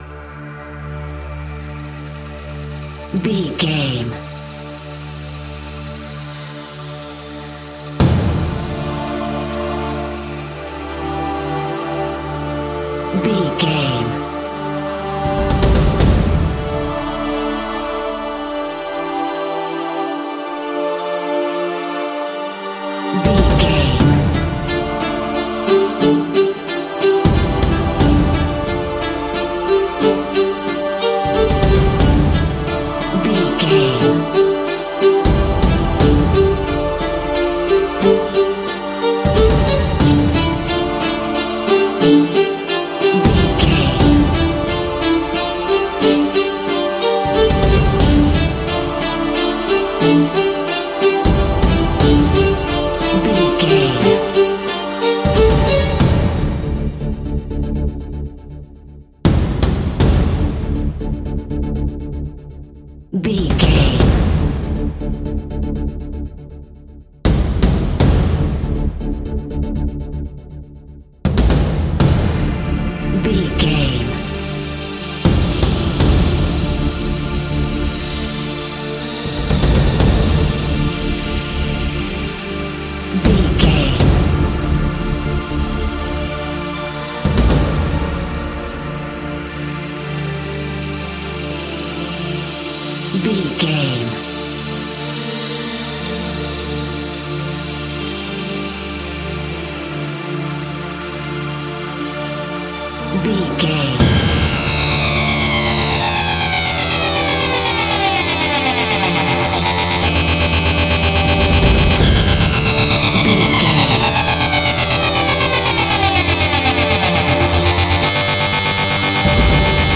Aeolian/Minor
strings
percussion
synthesiser
brass